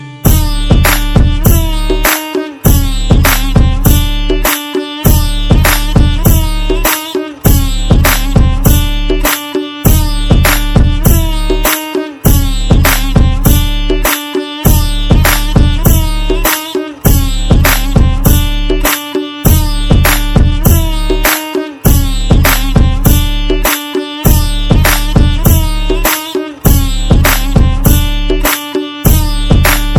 BGM Ringtones